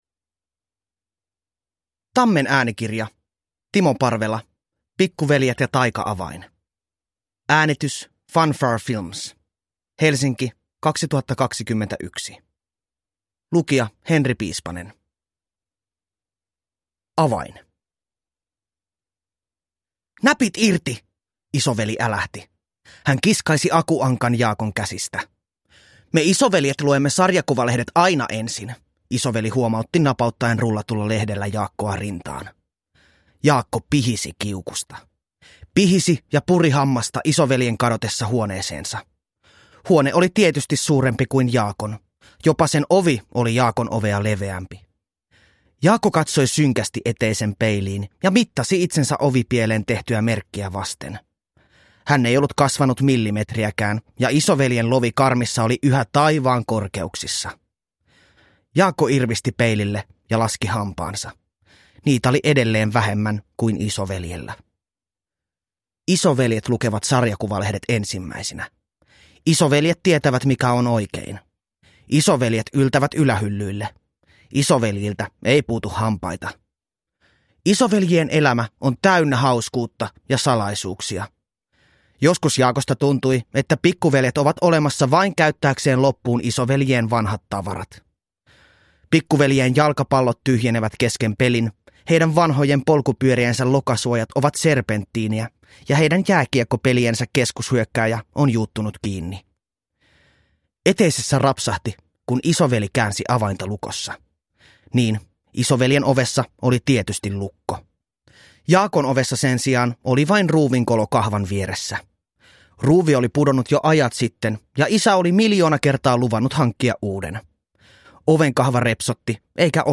Pikkuveljet ja taika-avain – Ljudbok – Laddas ner